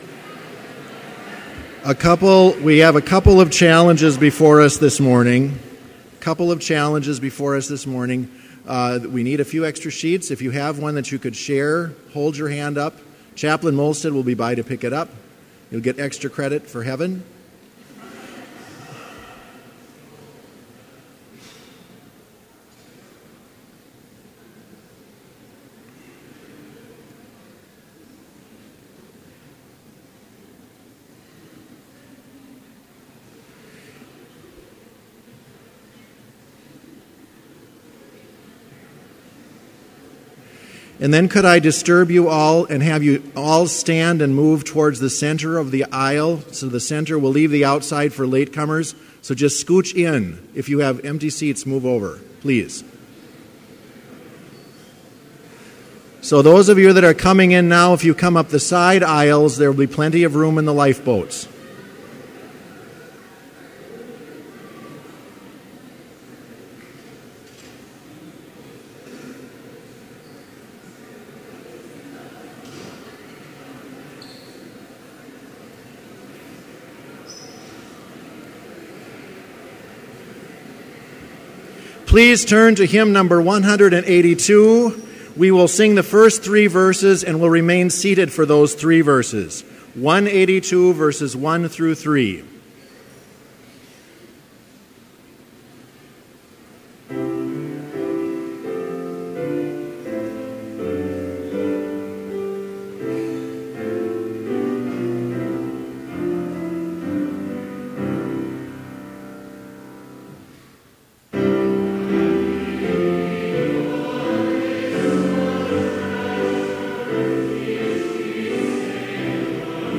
Complete service audio for Chapel - August 31, 2015